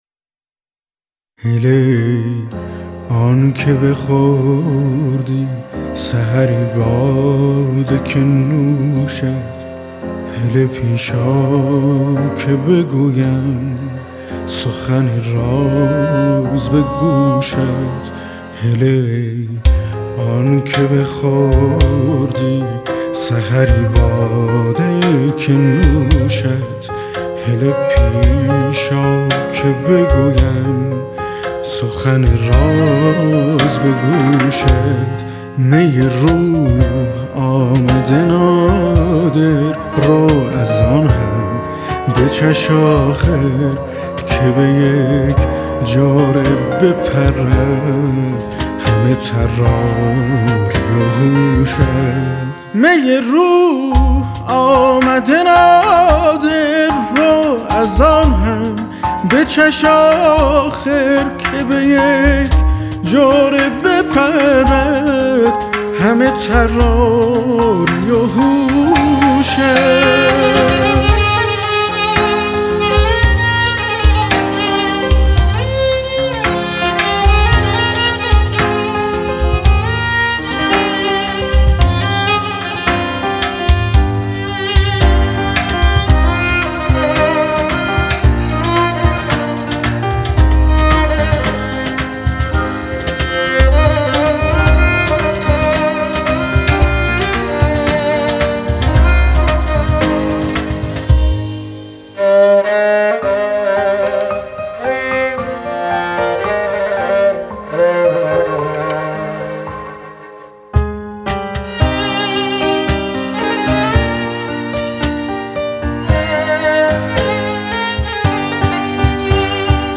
تنظیم و پیانو
کمانچه آلتو
سازهای کوبه ای